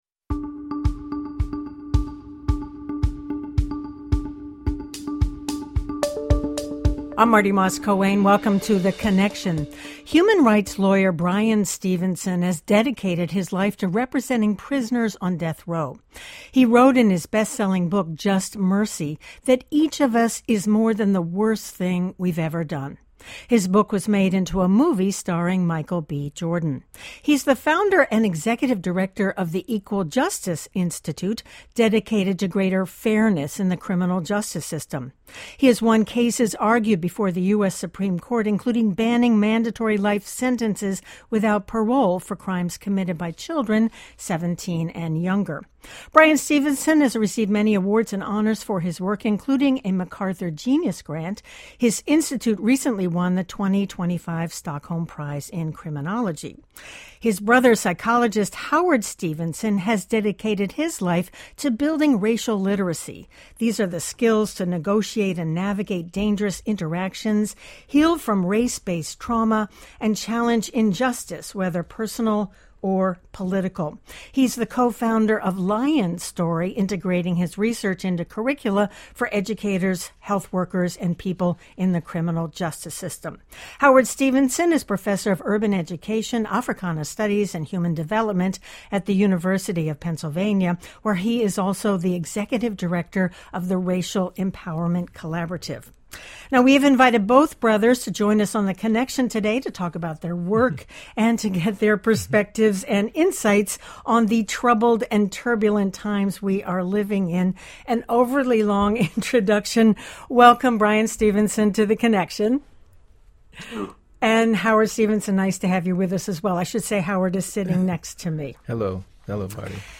Guests: William Giraldi, Richard Blanco, Bobby Rydell